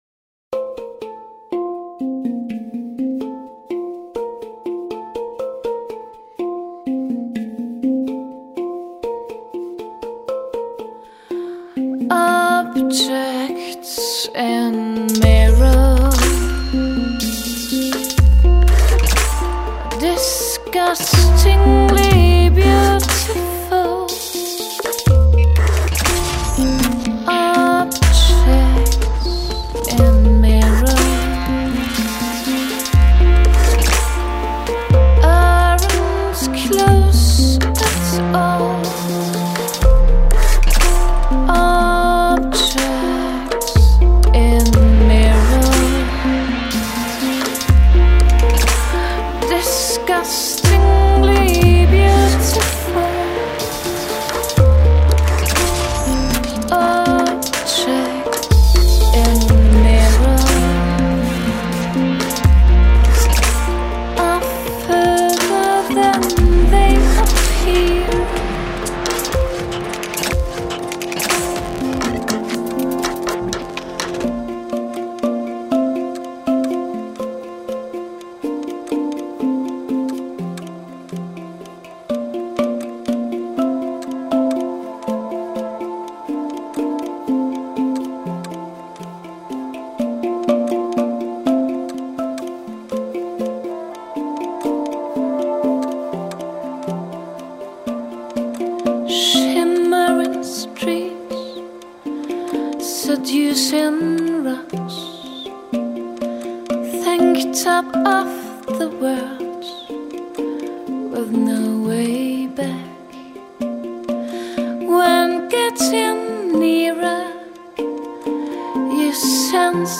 En bref un excellent hang band.